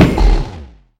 Minecraft Version Minecraft Version snapshot Latest Release | Latest Snapshot snapshot / assets / minecraft / sounds / mob / enderdragon / hit4.ogg Compare With Compare With Latest Release | Latest Snapshot